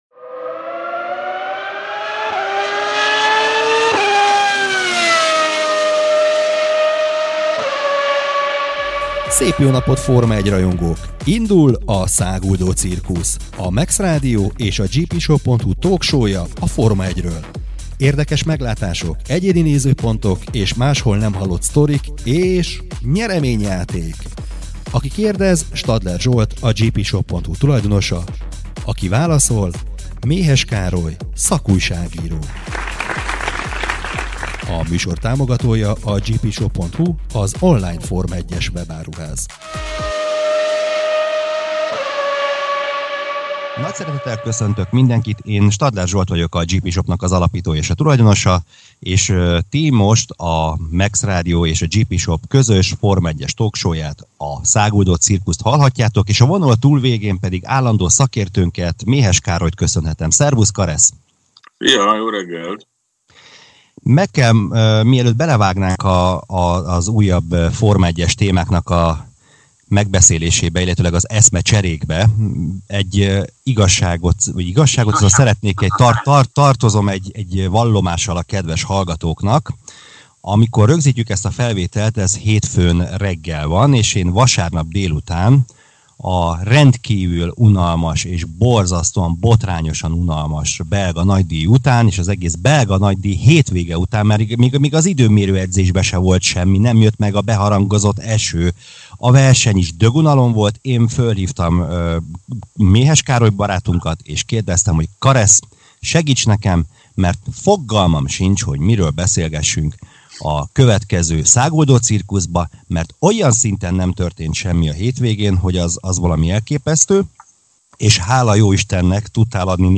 Íme a GPshop Forma 1-es talk showjának Száguldó Cirkusznak szeptember 3-i adása.